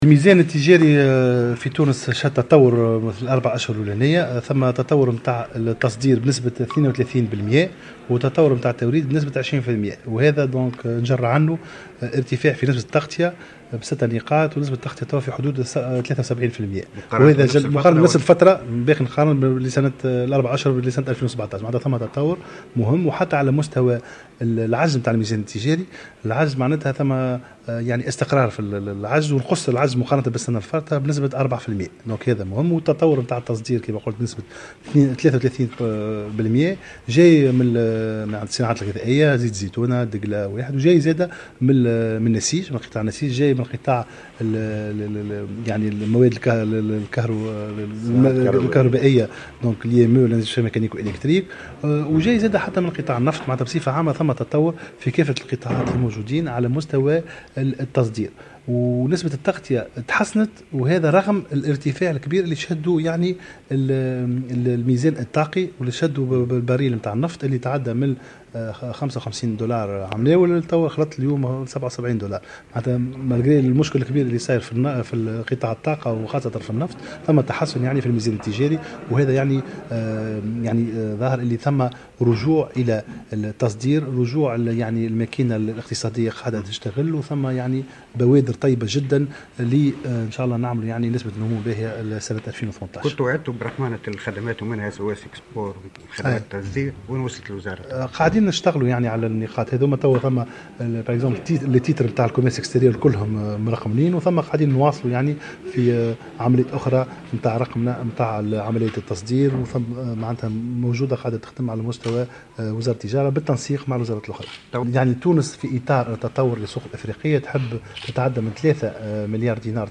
أكد وزير التجارة عمر الباهي في تصريح لمراسل الجوهرة "اف ام" خلال زيارته لولاية المهدية اليوم الإثنين أن الميزان التجاري سجل في الثلث الأول من سنة 2018 تطورا بــ6 نقاط مقارنة بنفس الفترة من سنة 2017 ليبلغ مستوى 73 بالمائة ليتقلص مستوى عجزه بـ4 نقاط.